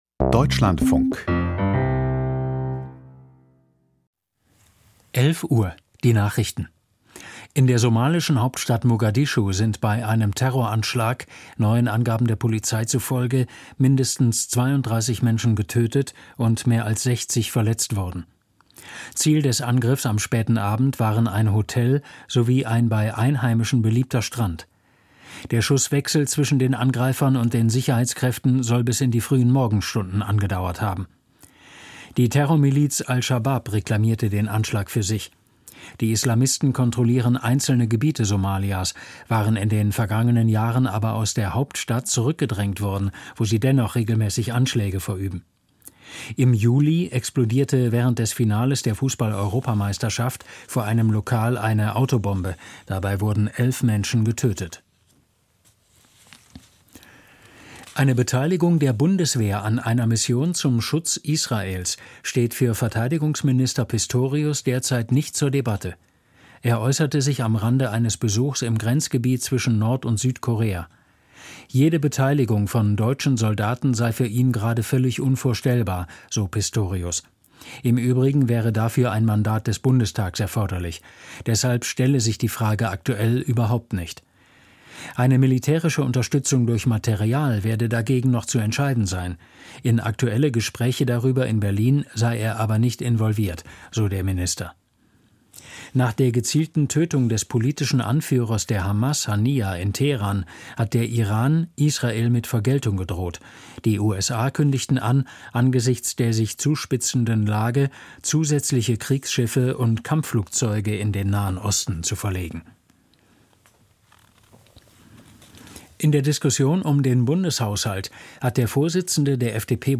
Nach Solingen und vor Landtagswahlen in Sachsen und Thüringen - Wie aufgeheizter Stimmung begegnen in den evangelisch geprägten Bundesländern? - Interview mit Heinrich Bedford-Strohm, Weltkirchenrats-Vorsitzender und Ex-EKD-Ratsvorsitzender - 30.08.2024